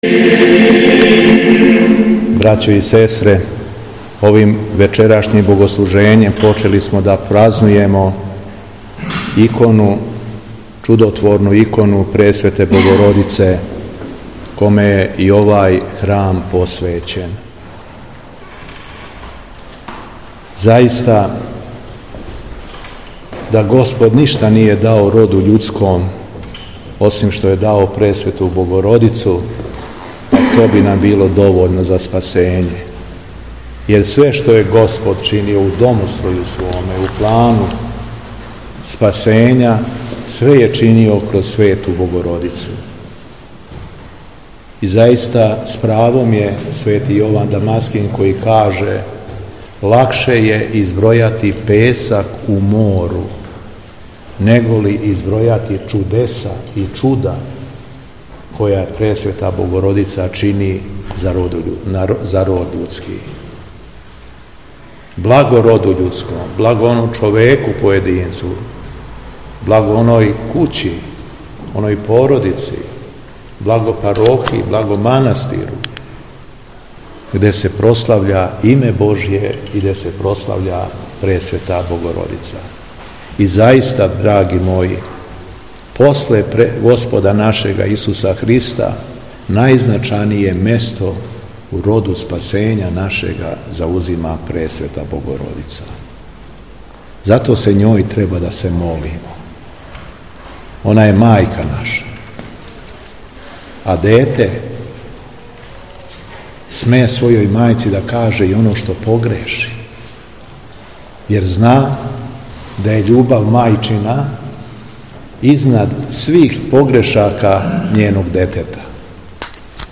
ПРАЗНИЧНО БДЕНИЈЕ У ХРАМУ ПРЕСВЕТЕ БОГОРОДИЦЕ ТРОЈЕРУЧИЦЕ У МАЛИМ ПЧЕЛИЦАМА
По завршетку службе, Митрополит се обратио верном народу, рекавши:
Беседа Његовог Високопреосвештенства Митрополита шумадијског г. Јована